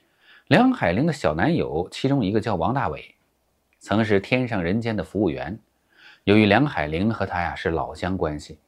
舒缓语音参考音频